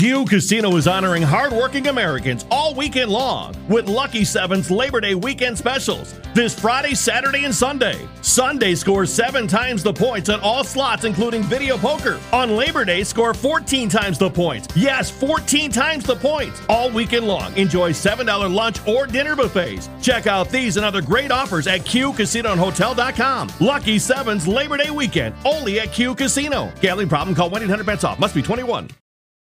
Casino Audio Ad Sample